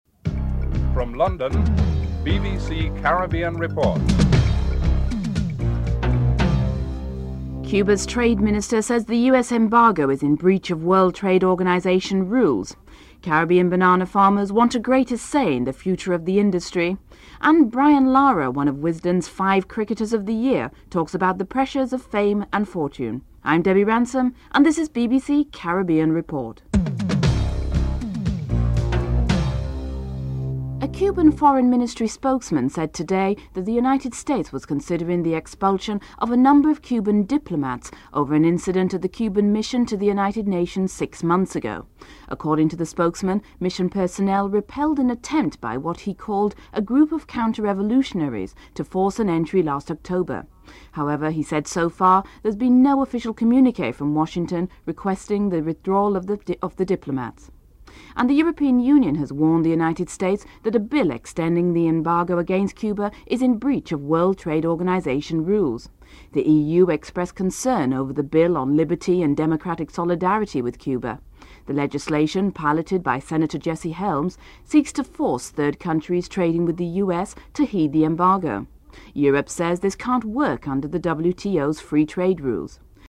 Lara talks about the pressure of fame and fortune.